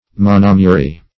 Definition of monomyary. What does monomyary mean? Meaning of monomyary. monomyary synonyms, pronunciation, spelling and more from Free Dictionary.
Search Result for " monomyary" : The Collaborative International Dictionary of English v.0.48: Monomyarian \Mon`o*my"a*ri*an\, Monomyary \Mon`o*my"a*ry\, a. (Zool.)